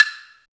176TTCLAVE-L.wav